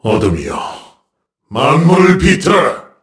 KaselB-Vox_Skill1_kr_b.wav